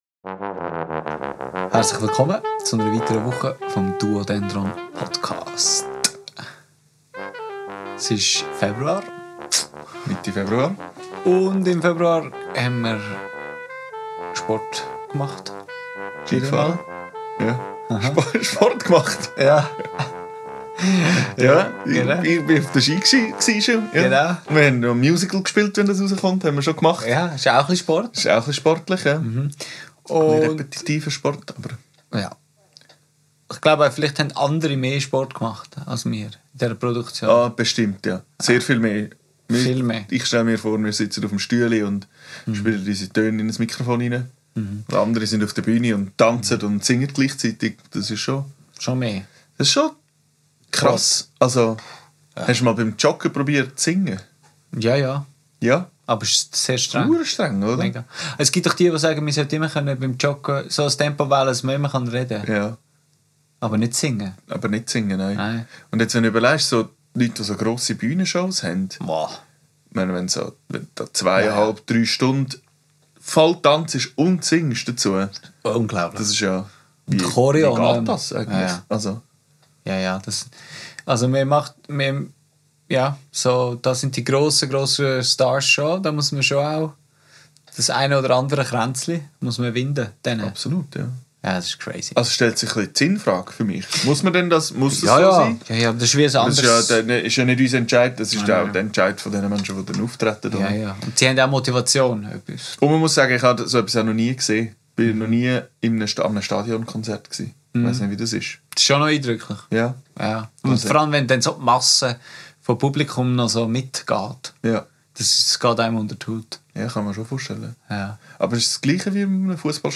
Was ist der Unterschied zu einem Konzert im kleinen Rahmen? Ein Gespräch über kleine und grosse Bühnen mit anschliessender Improvisation und der Empfehlung sich NPR Tiny Desk Konzerte anzuhören.